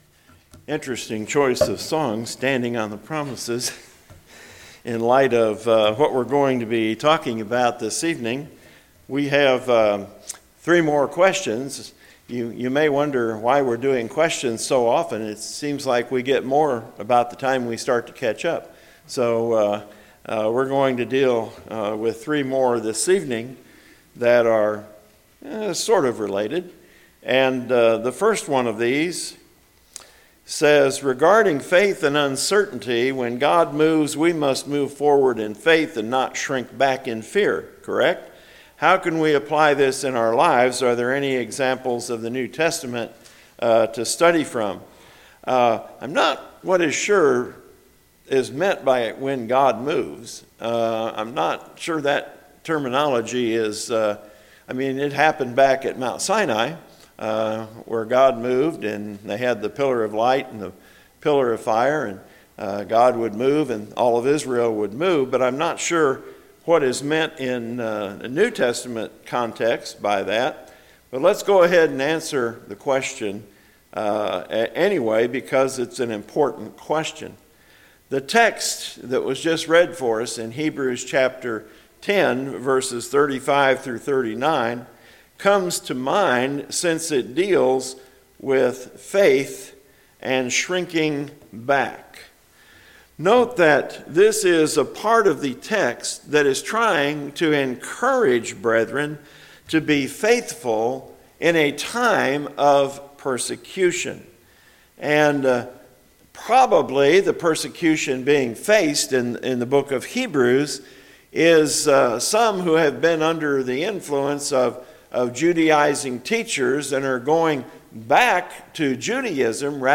Recorded audio gospel sermons from the pulpit of South Seminole church of Christ.